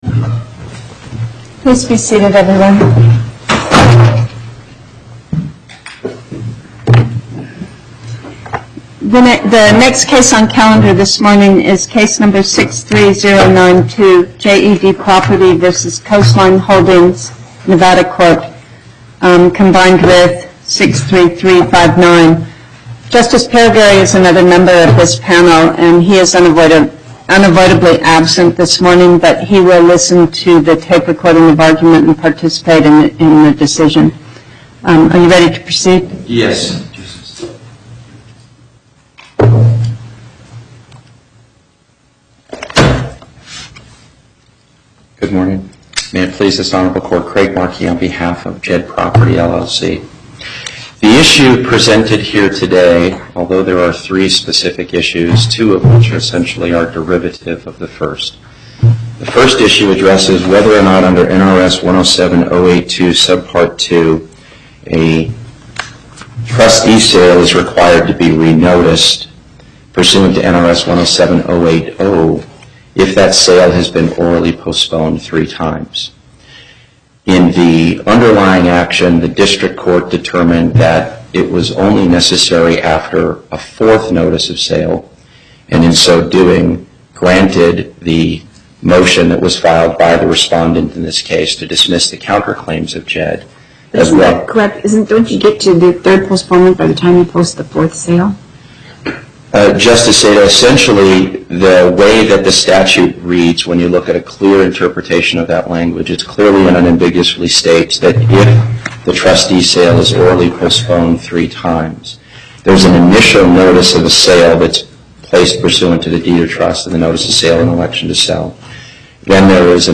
Location: Las Vegas Before the Northern Nevada Panel, Justice Pickering Presiding